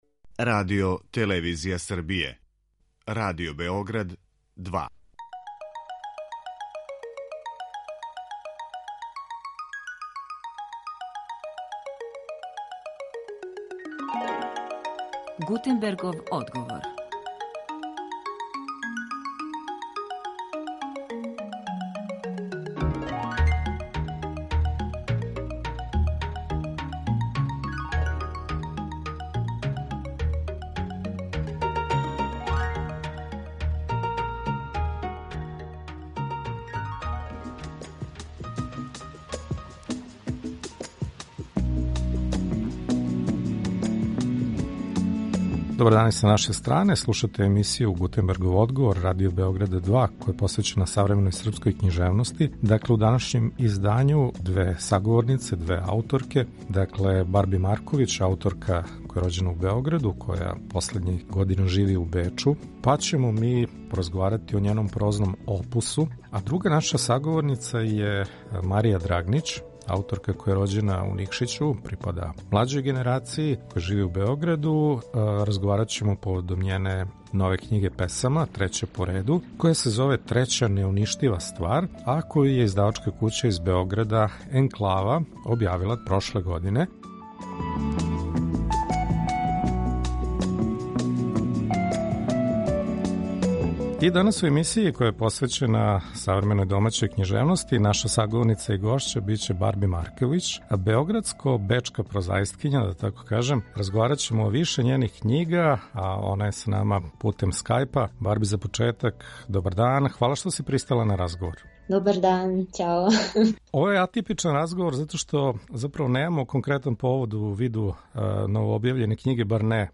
Саговорнице